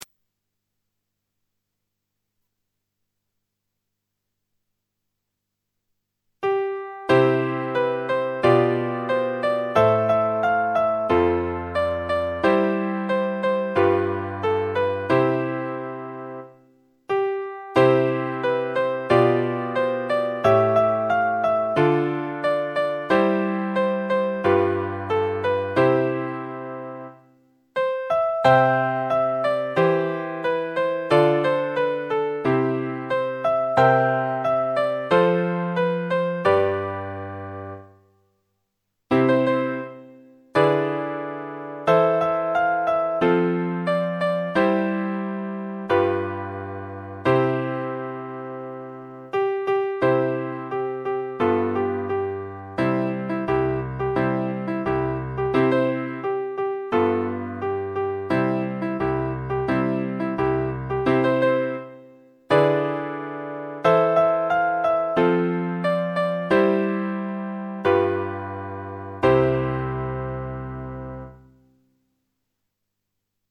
お子さんやお孫さんが歌う際にはピアノ伴奏のカラオケとして使用できます。
(ＭＰ３版は、パソコンによっては演奏時に、スクラッチ音様のノイズを伴うことがあります。）